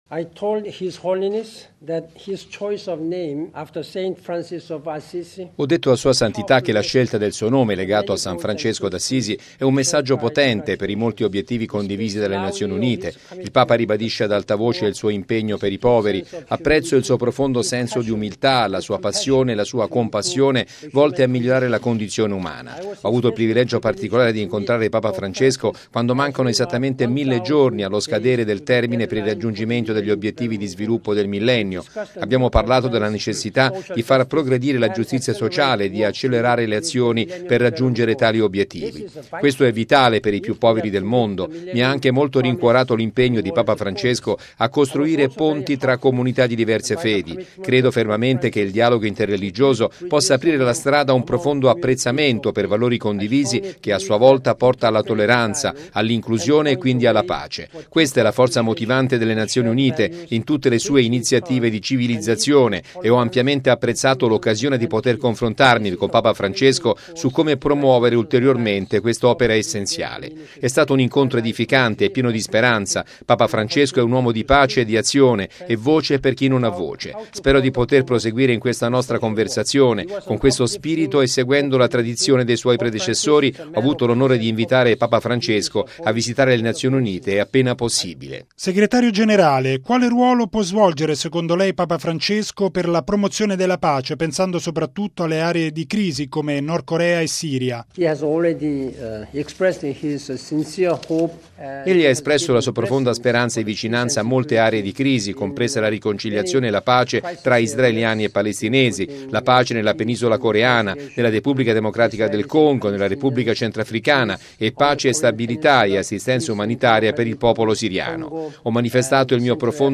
◊   Dopo il colloquio con il Papa in Vaticano, il segretario generale dell’Onu ha incontrato a Roma un gruppo ristretto di giornalisti.